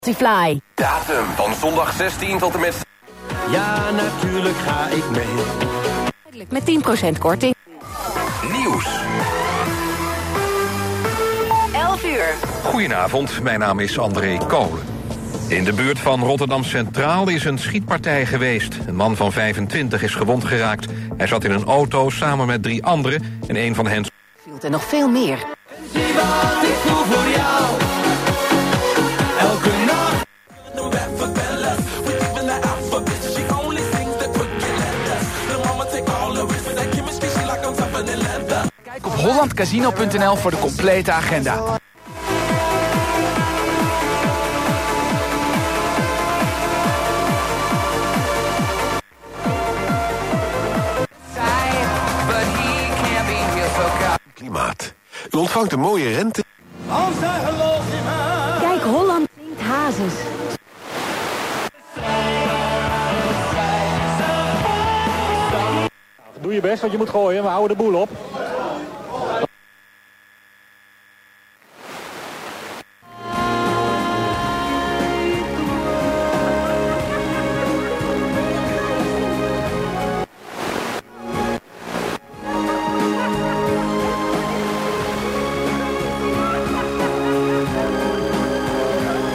Wideband FM reception with it’s built in FM radio:
This is while using the search function on the FM band (which is very fast by the way). There is no stereo output available.
Okay, it is not very rich in bass tones.
UV-5RE-FM-radio-reception-audio-sound-on-speaker-output.mp3